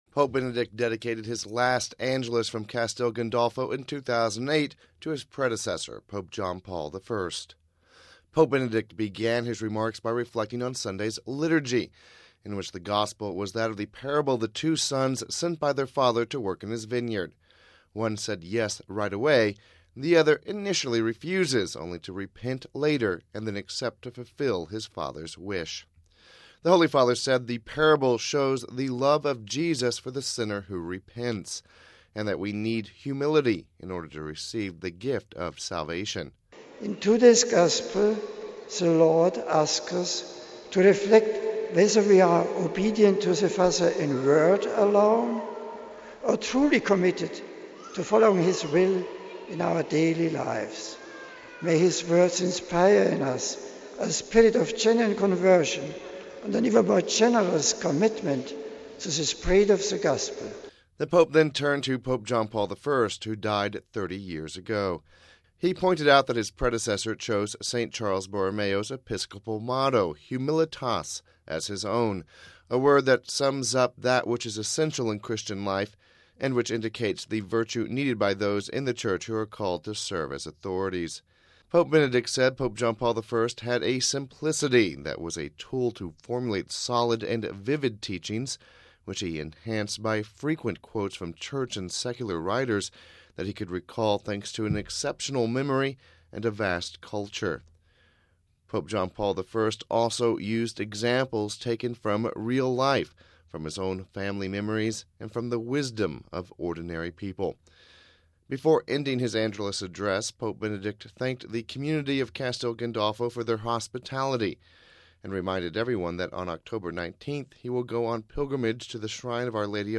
Home Archivio 2008-09-28 16:11:27 Pope Benedict XVI remembers Pope John Paul I (28 Sep 08 - RV) Pope Benedict XVI remembered Pope John Paul I on the 30th anniversary of his death. We have this report...